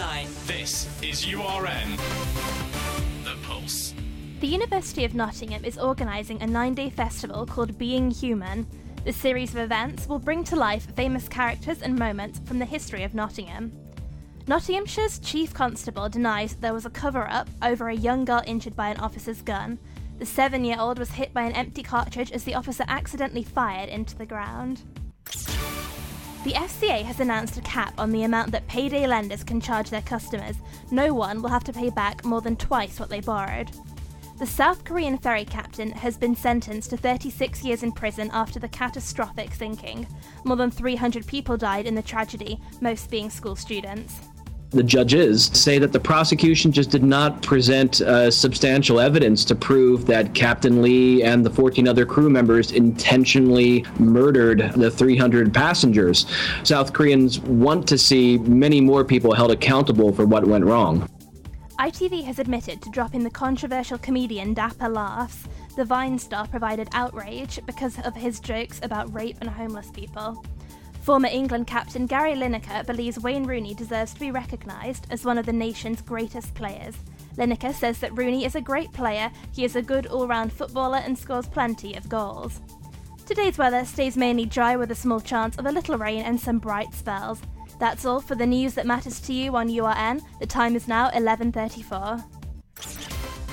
Your Latest Headlines - Tuesday 11th October